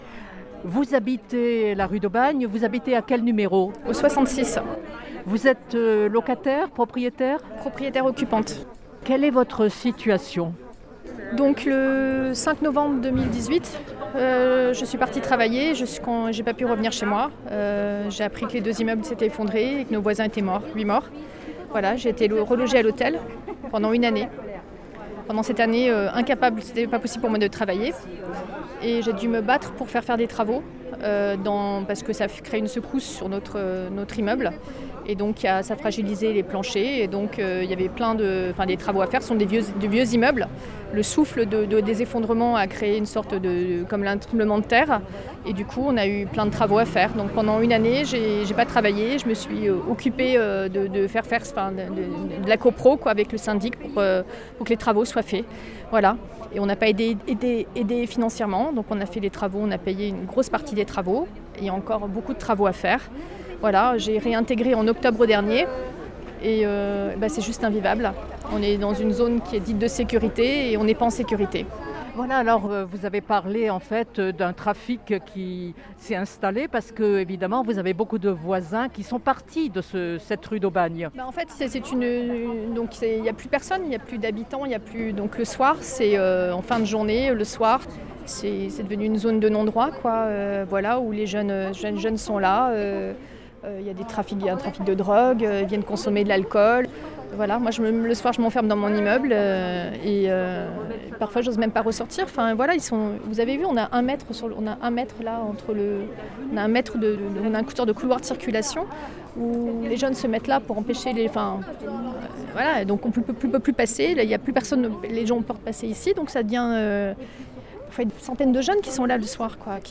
son_copie_petit-426.jpgUne jeune femme, propriétaire occupante, raconte sa vie de délogée, sa souffrance.